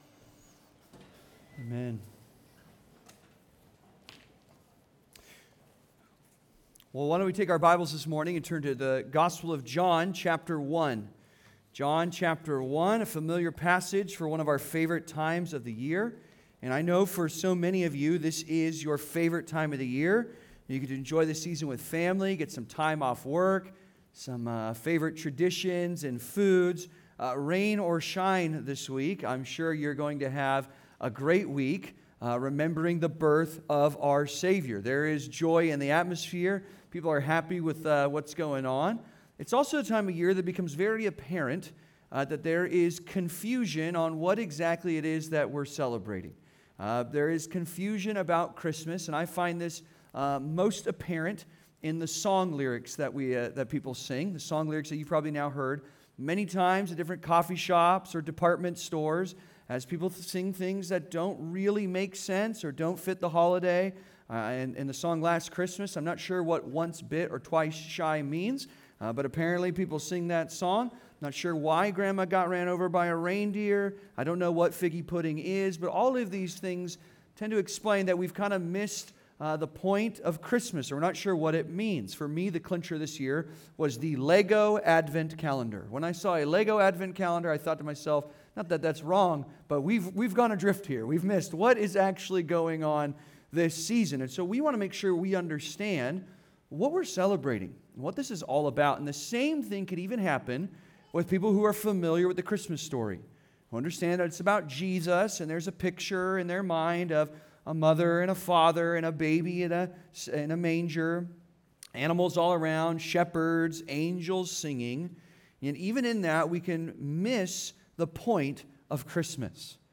Rediscovering the Glory of Christmas (Sermon) - Compass Bible Church Long Beach